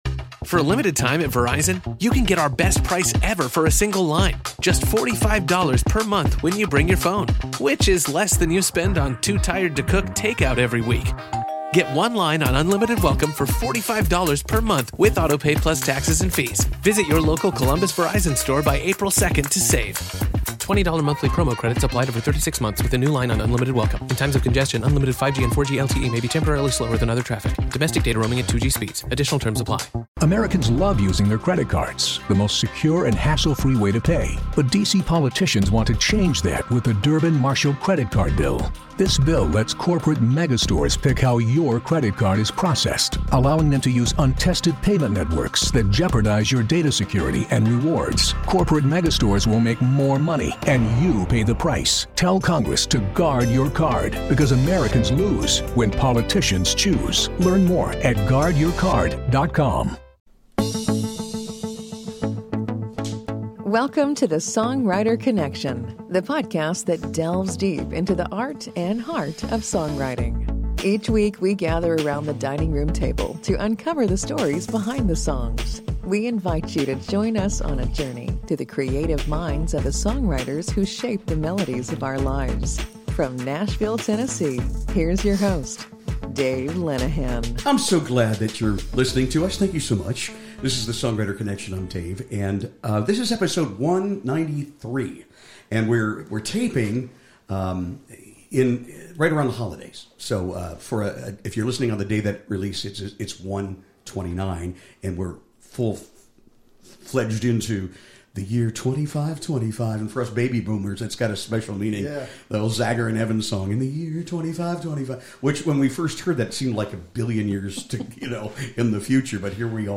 Tune in for great conversation, a few laughs, and, of course, some incredible music.